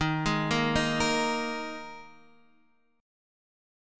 Ebsus4 Chord